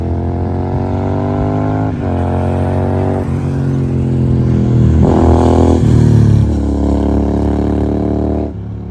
Growler Exhaust Flyby
• Sporty note with deep bass
Growler-flyby.mp3